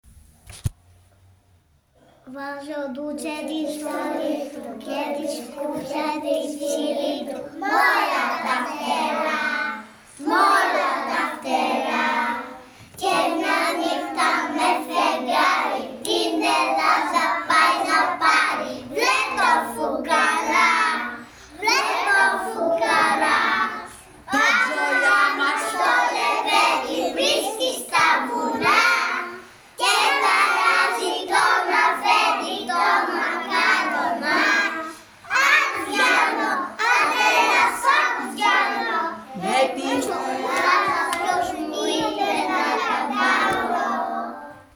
Κάναμε μια γιορτούλα με ποιήματα, τραγούδια και παρέλαση για την επέτειο της 28ης Οκτωβρίου 1940!
Τα παιδιά τραγουδάνε τον “Ντούτσε”